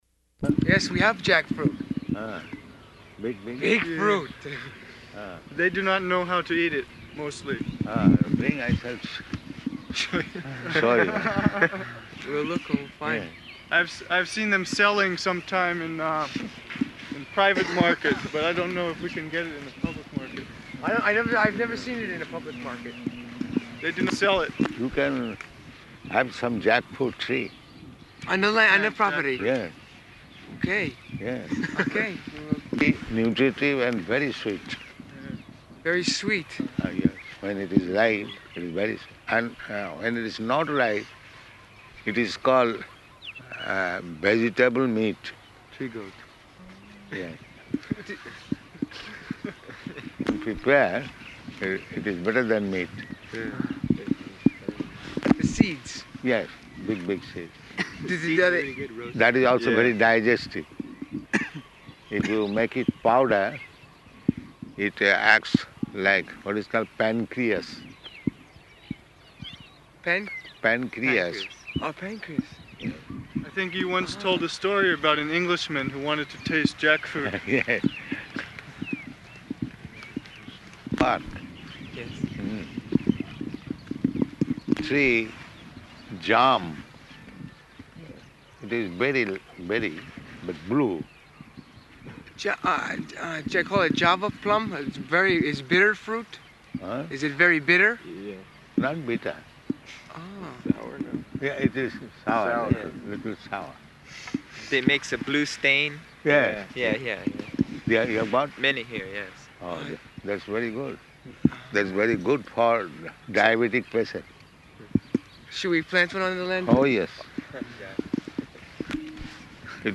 Morning Walk --:-- --:-- Type: Walk Dated: June 16th 1975 Location: Honolulu Audio file: 750616MW.HON.mp3 Devotee (1): Yes, we have jackfruit.